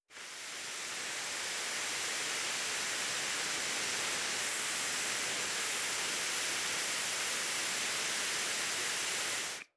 Terrarum/assets/mods/basegame/audio/effects/explosion/fuse_continue.wav at 39cfc3a4d945afec5c1ecb4def2fb479c3ce0bfe
fuse_continue.wav